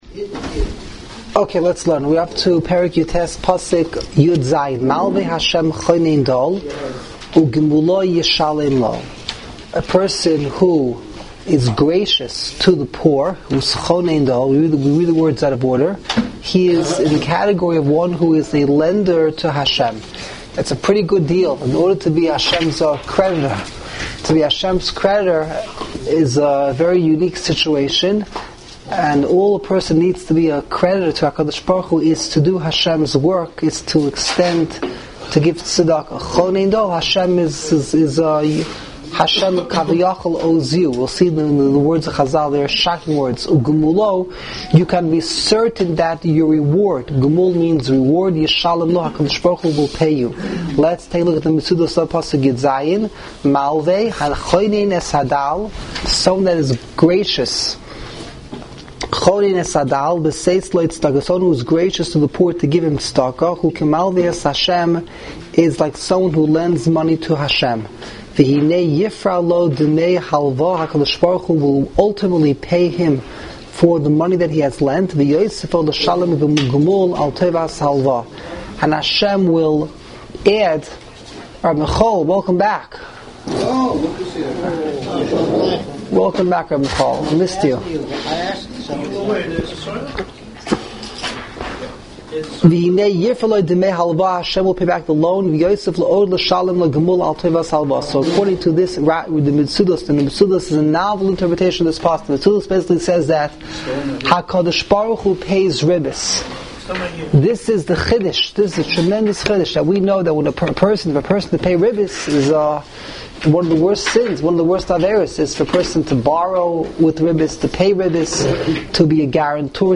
Live Daily Shiurim